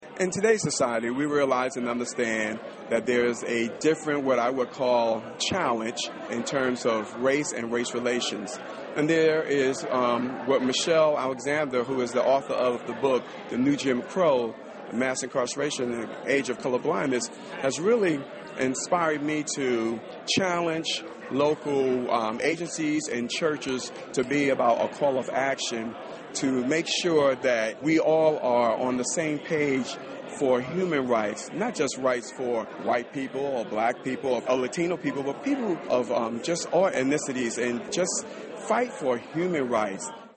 A large crowd at Blinn College heard a message of encouragement at the 30th Annual Black History Breakfast, Saturday morning at Blinn College.